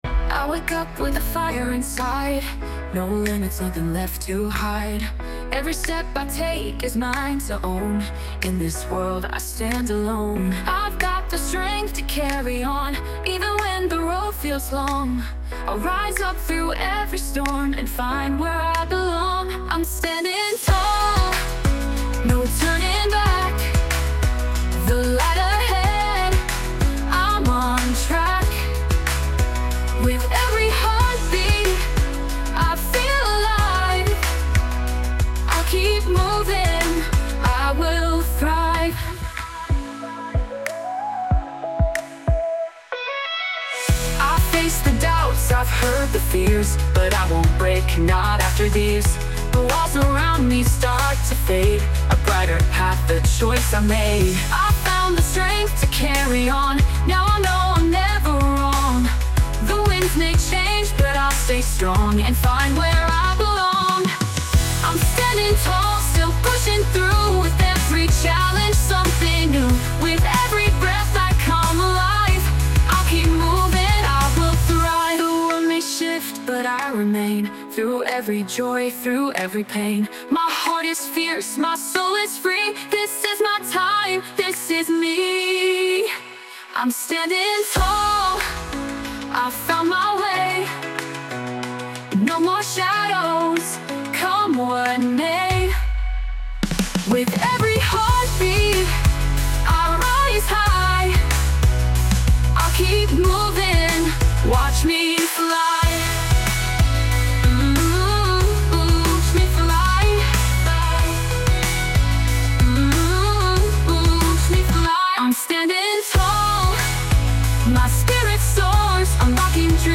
洋楽女性ボーカル著作権フリーBGM ボーカル
著作権フリーオリジナルBGMです。
女性ボーカル（洋楽・英語）曲です。
力強い女性ボーカルの曲・洋楽をイメージして制作しました！